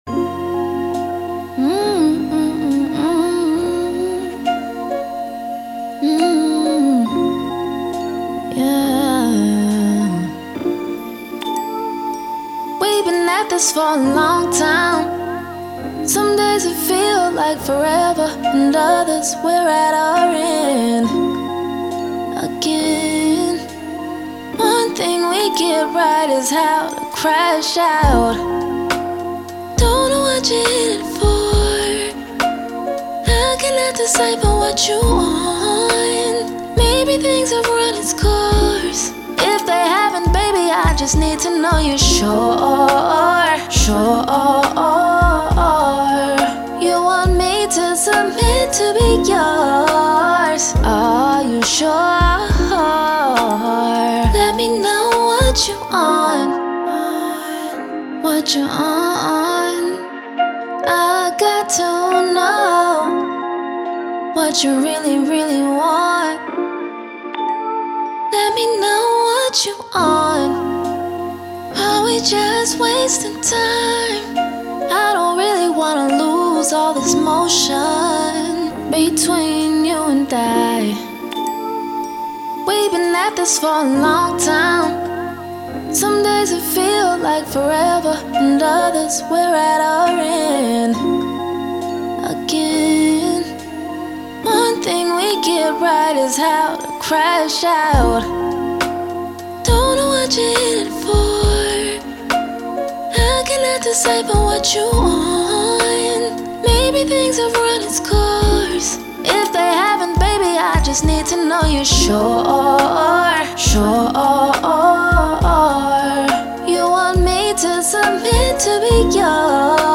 R&B
A Minor